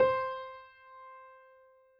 piano_060.wav